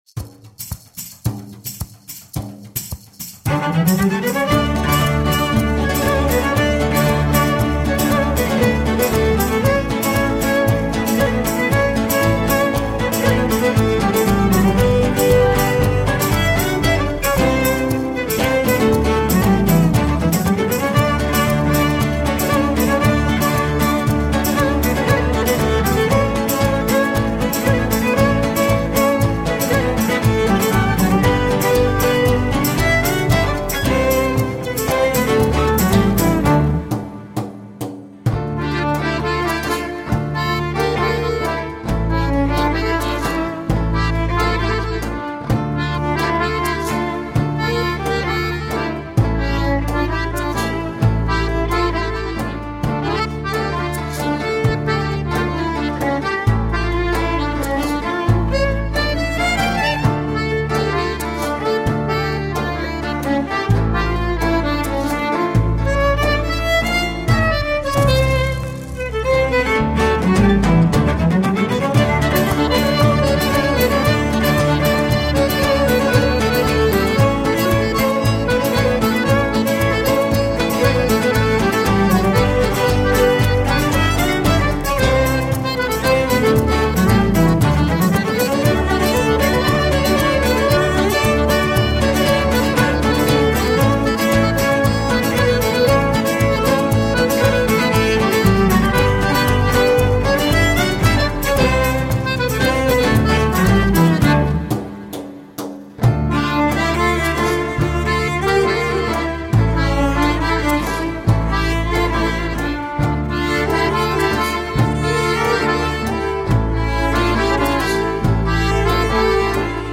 Early music meets global folk at the penguin café.